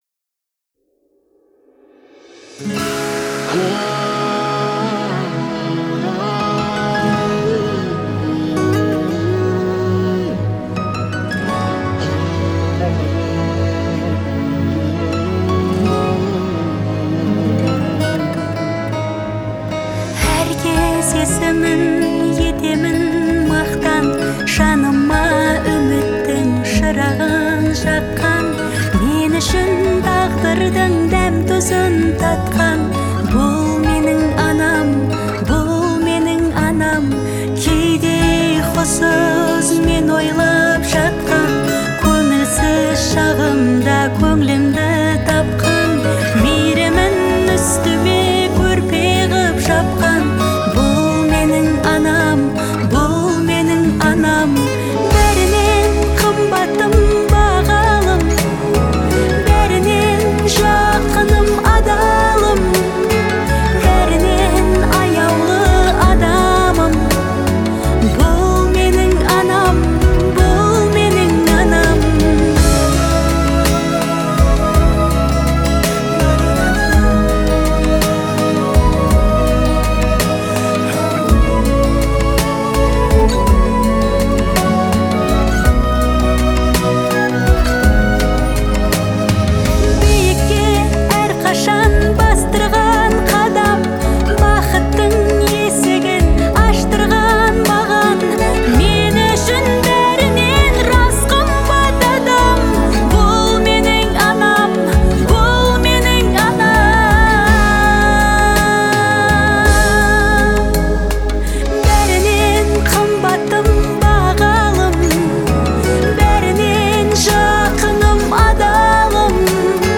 трогательная песня в жанре казахской народной музыки